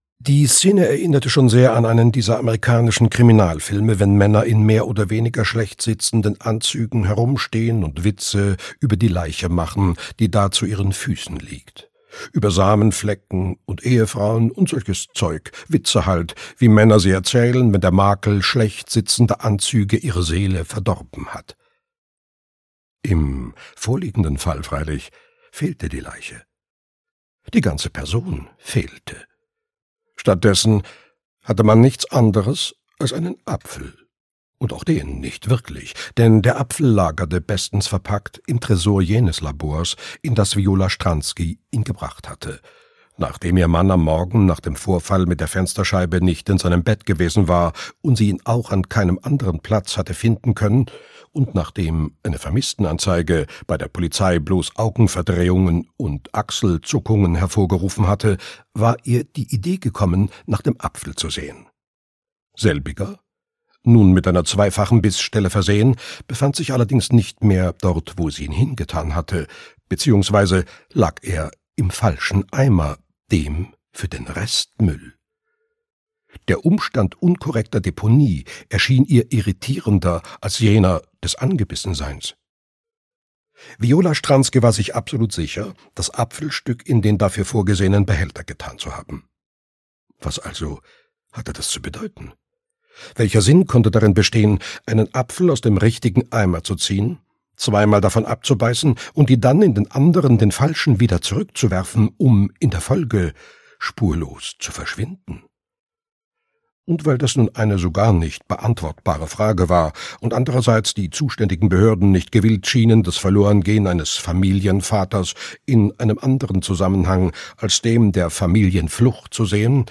Hörbuch Die feine Nase der Lilli Steinbeck, Heinrich Steinfest.